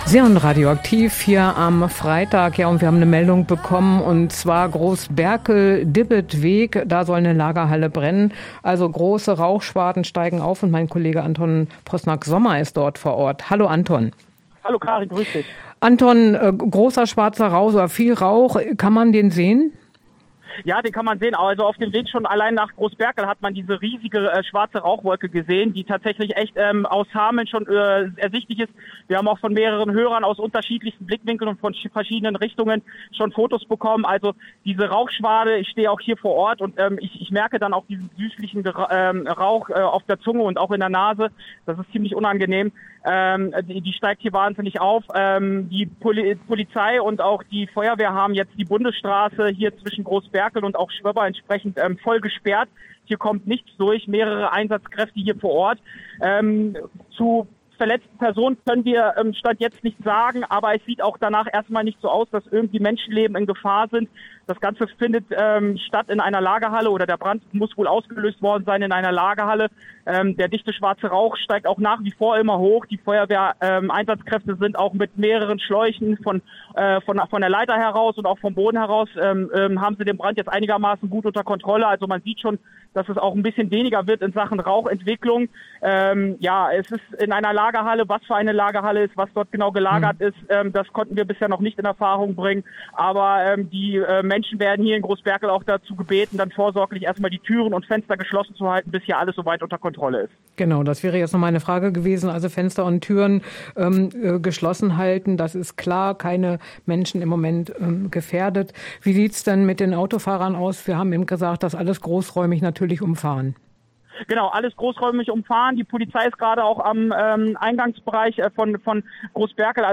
Unser Reporter berichtet vom Brand einer Lagerhalle in Groß Berkel
Aktuelle Lokalbeiträge